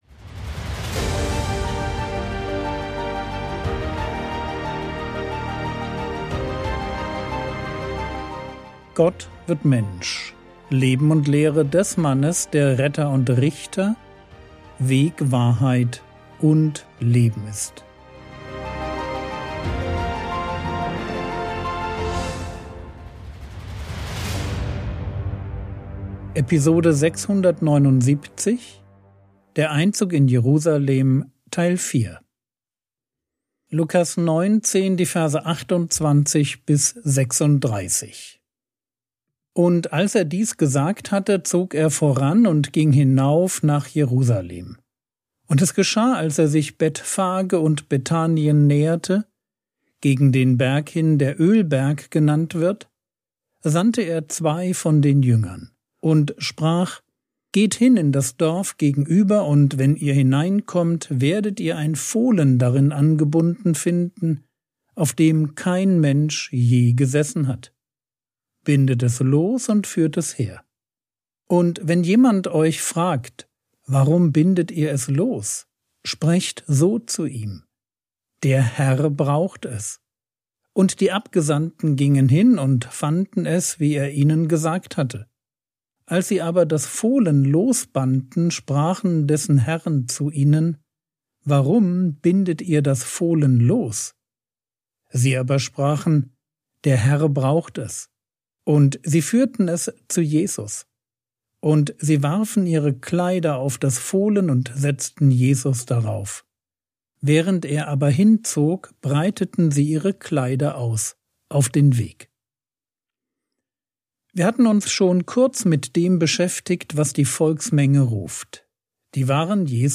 Episode 679 | Jesu Leben und Lehre ~ Frogwords Mini-Predigt Podcast